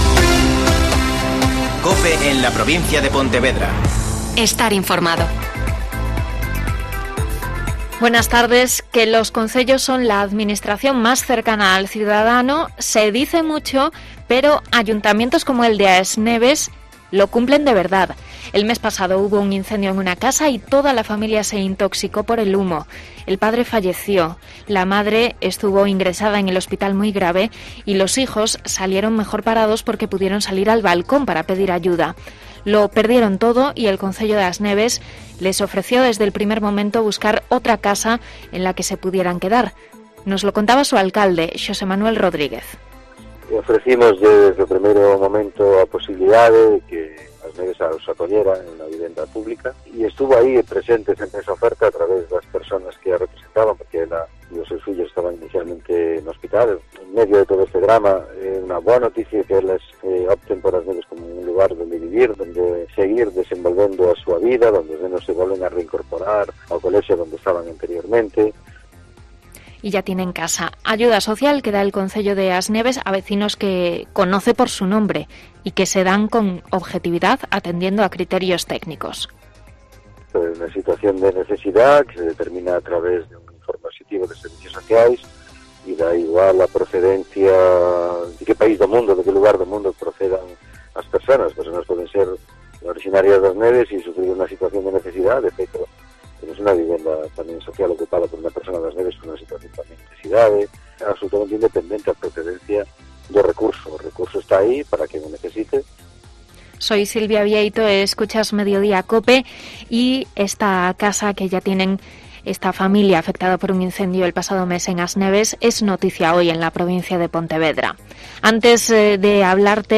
Mediodía COPE en la Provincia de Pontevedra (Informativo 14:20h)